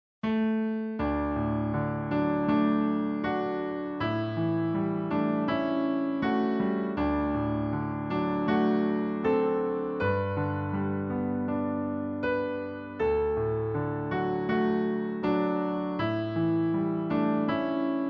Piano Solo
Downloadable Instrumental Track